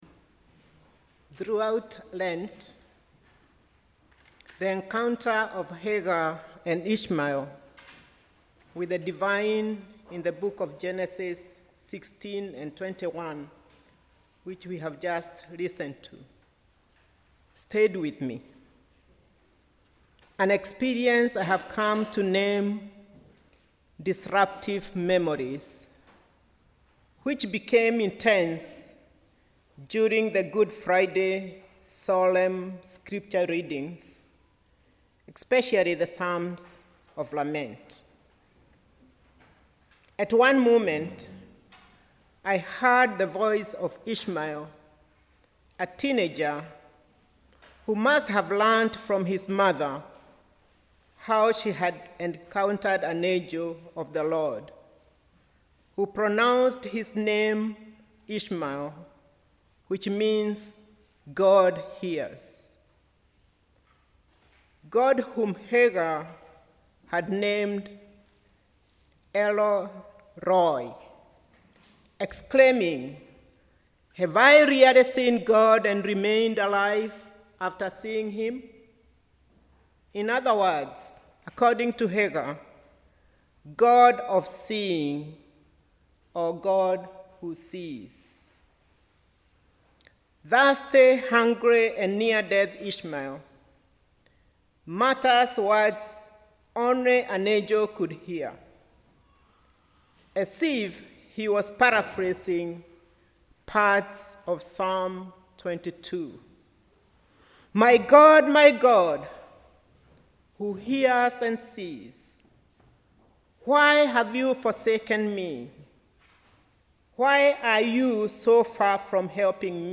Sermon: ‘Disruptive memories’
Fourth Sunday of Easter 2024